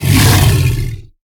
Sfx_creature_snowstalker_aggro_vox_01.ogg